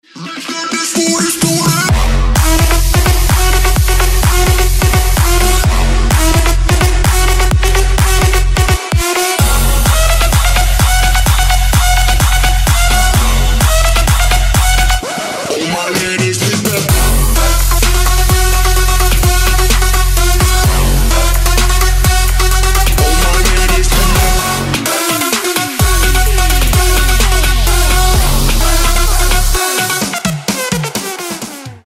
Electronic
EDM
club
progressive house